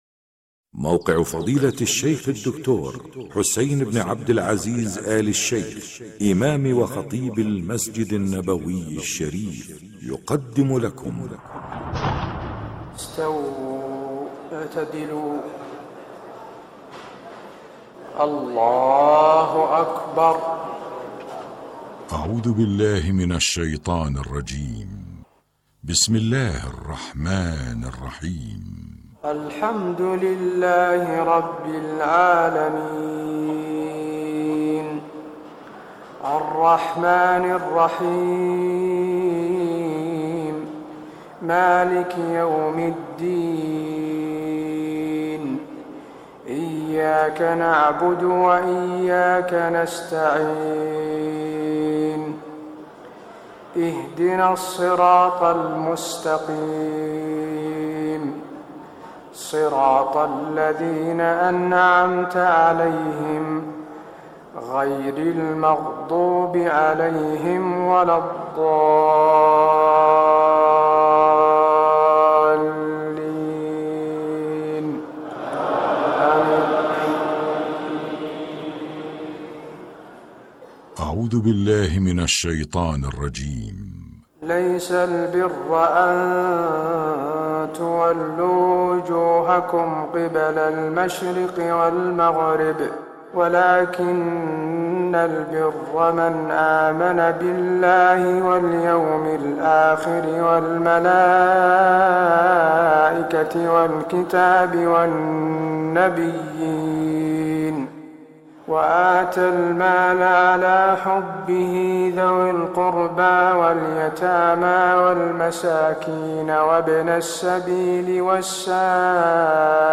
تهجد ليلة 22 رمضان 1425هـ من سورة البقرة (177-202) Tahajjud 22 st night Ramadan 1425H from Surah Al-Baqara > تراويح الحرم النبوي عام 1425 🕌 > التراويح - تلاوات الحرمين